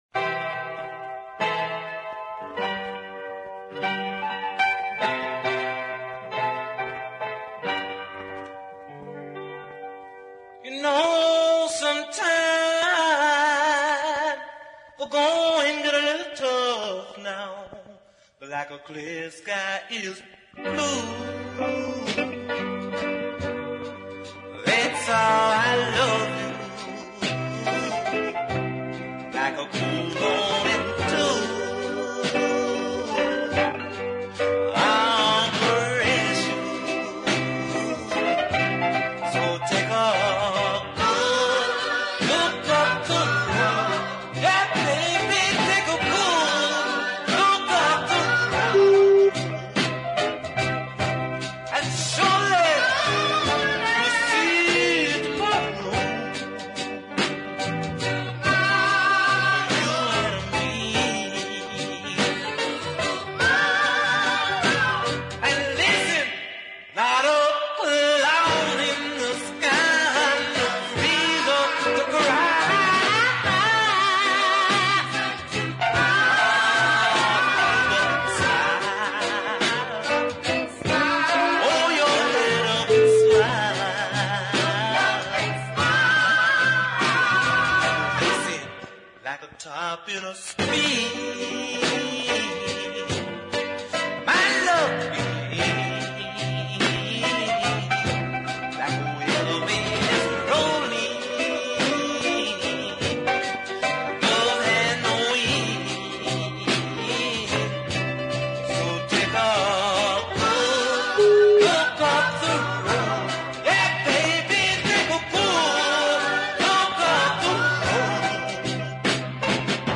Detroit singer
the sanctified backing vocals